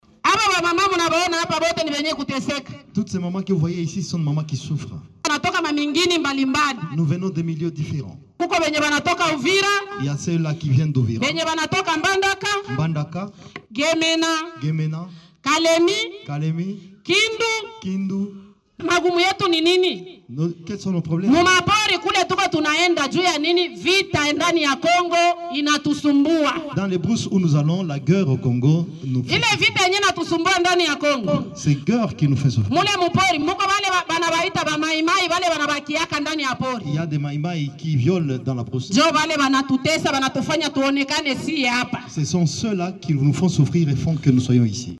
L’une des victimes des atrocités en parle